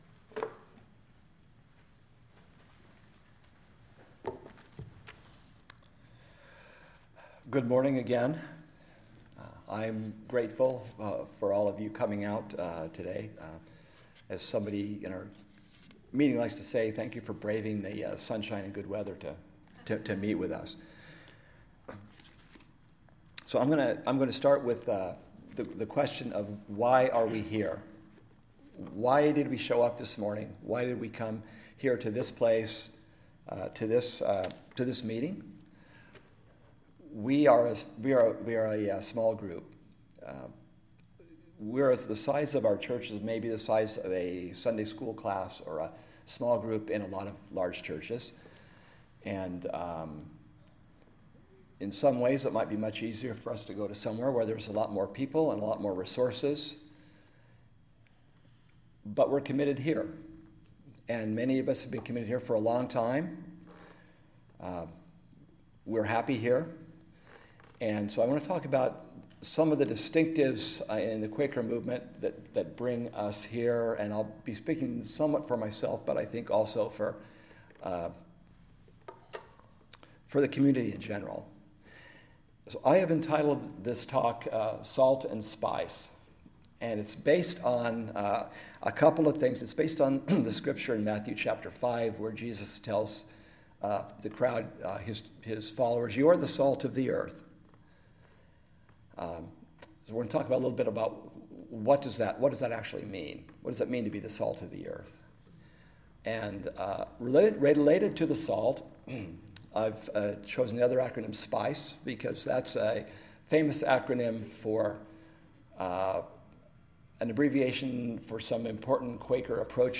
Listen to the most recent message from Sunday worship at Berkeley Friends Church, “Salt and Spice.”